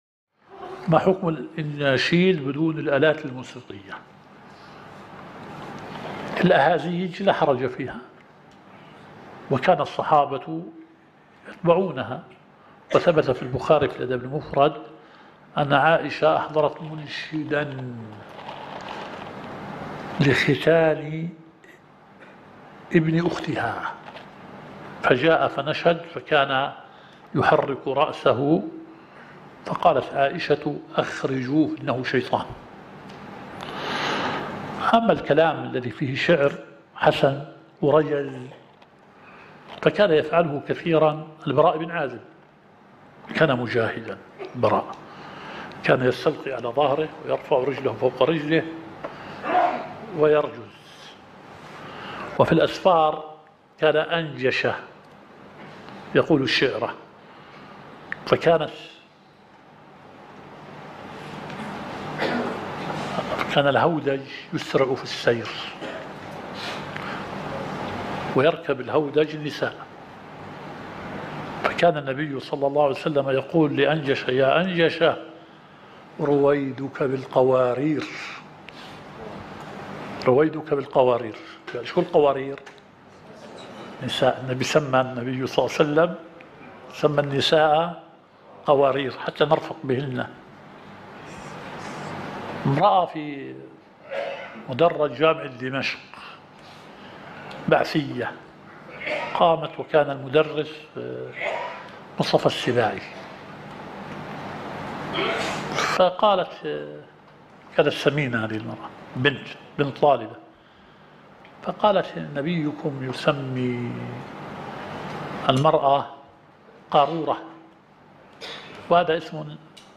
الدورة الشرعية الثالثة للدعاة في اندونيسيا – منهج السلف في التعامل مع الفتن – المحاضرة الثالثة.